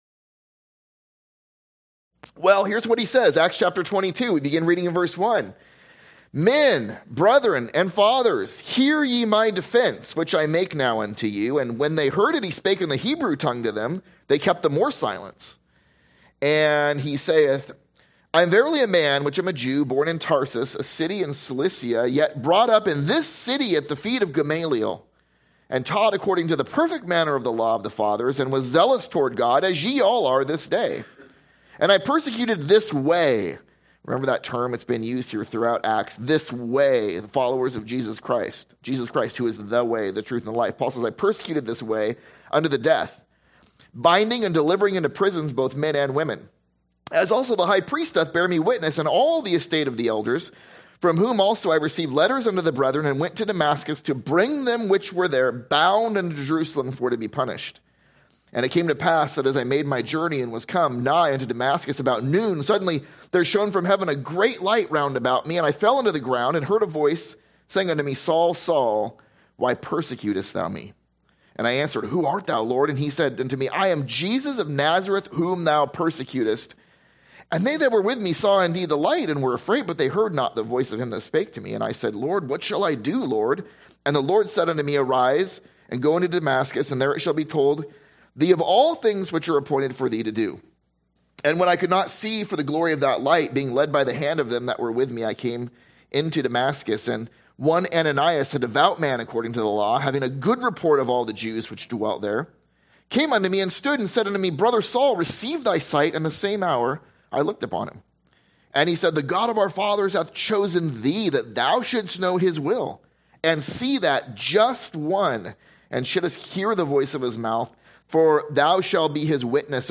/ A Sunday School series through the book of Acts.